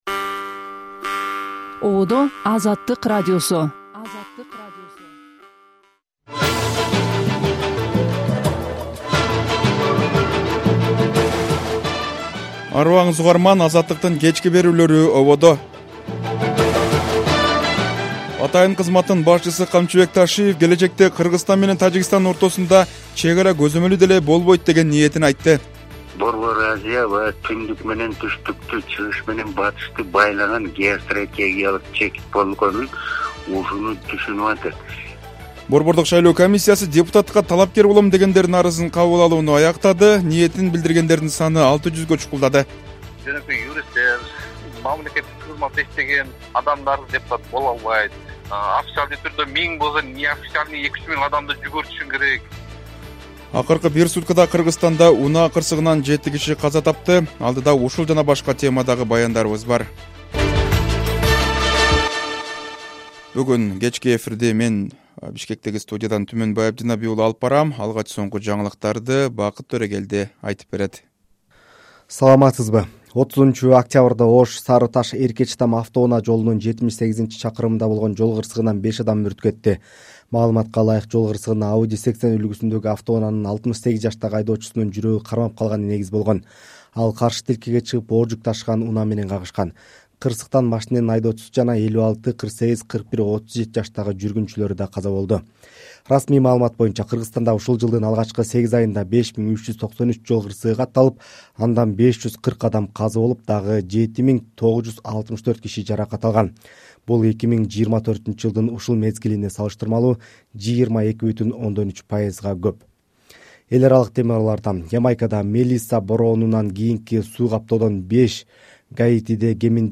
Жаңылыктар | 31.10.2025 | Бир суткада жети өлүм.